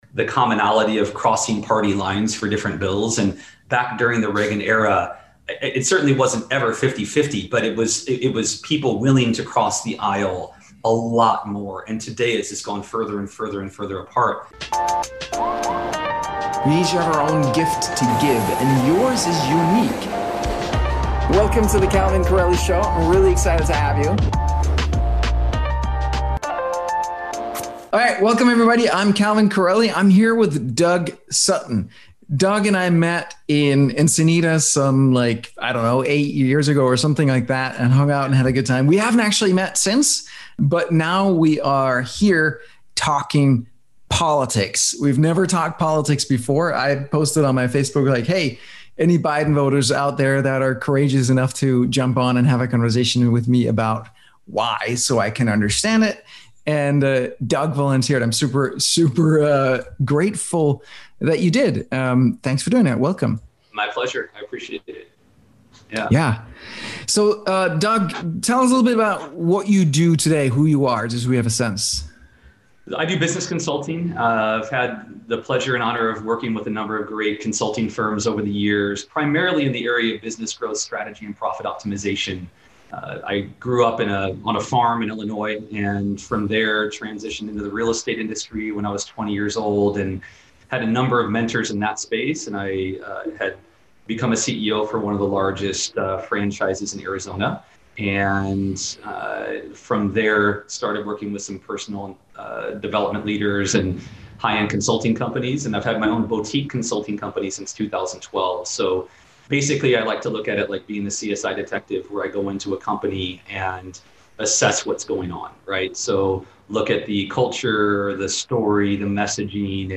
The best argument I've heard for Biden — Dialog between a Biden and Trump supporter